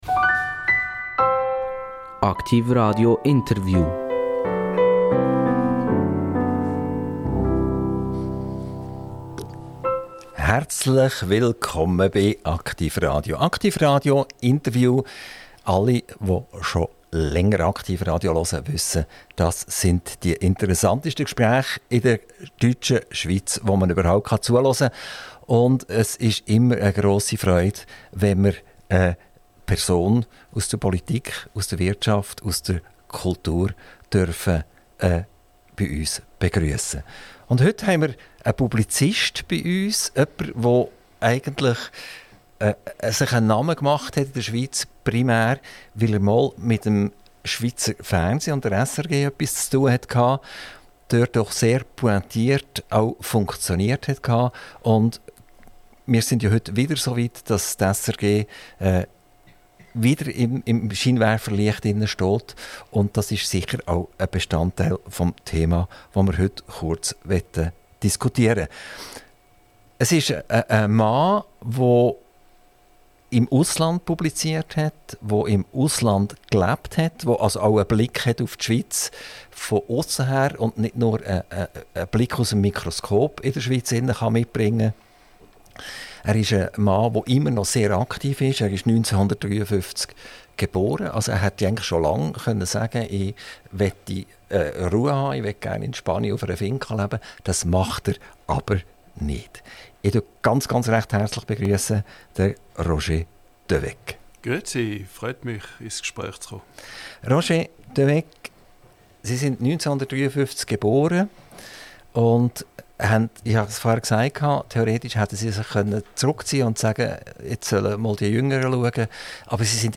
INTERVIEW - Roger de Weck - 22.11.2024 ~ AKTIV RADIO Podcast
Am 22.11. besuchte uns Roger de Weck, Autor, Referent und Moderator